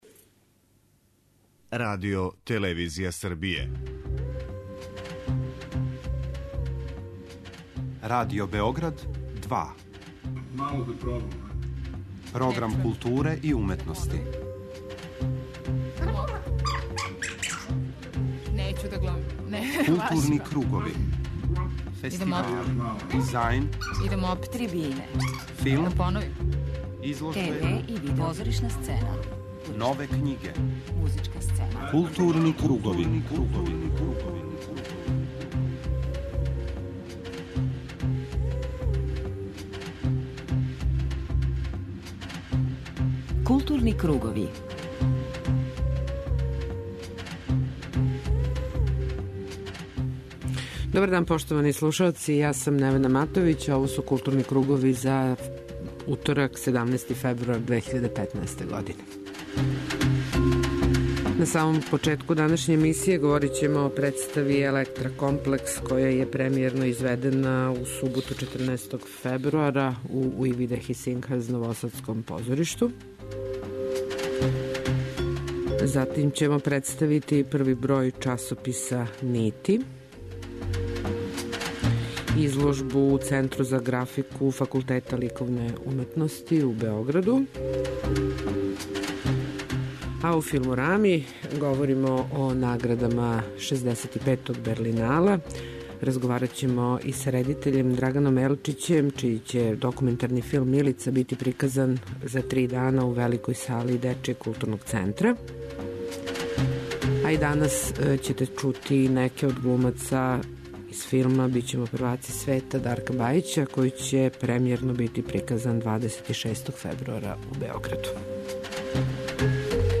На почетку темата ћете чути како је протекла завршна конференција за новинаре 65. Берлинала, међународног филмског фестивала који је завршен у недељу 15. фебруара Берлину.